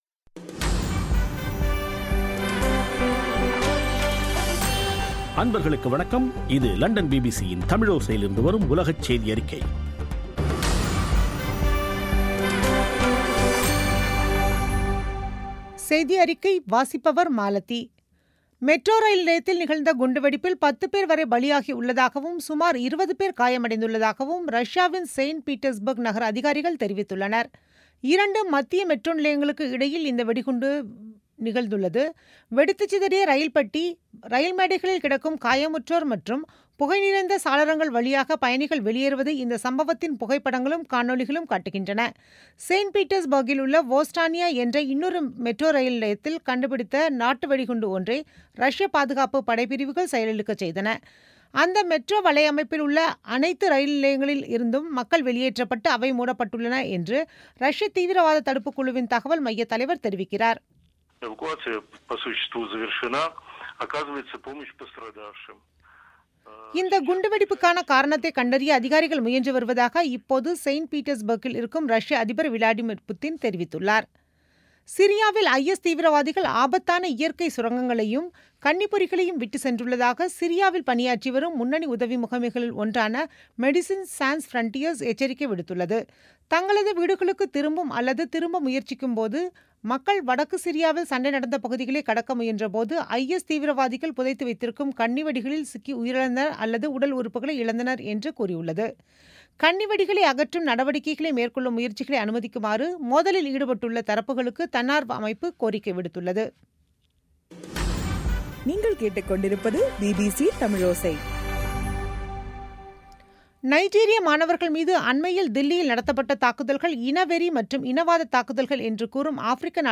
பிபிசி தமிழோசை செய்தியறிக்கை (03/04/2017)